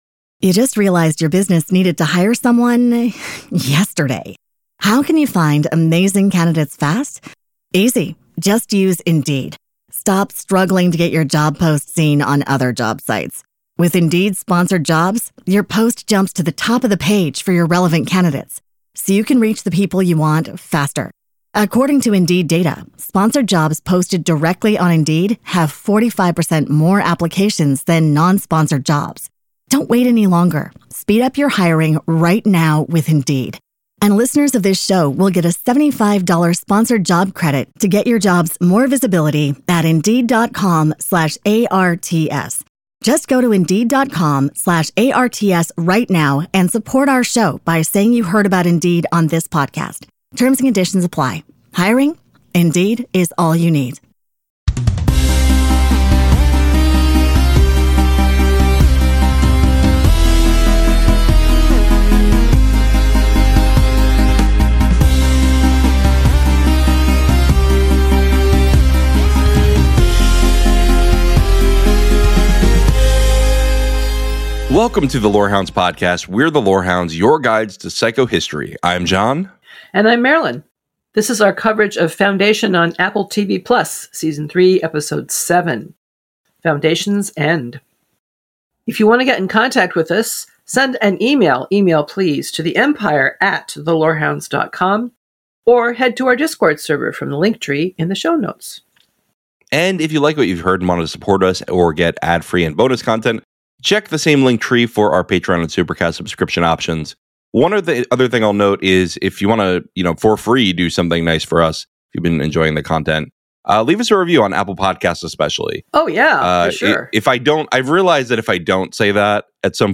and audience questions lead the conversation into some deep lore territory.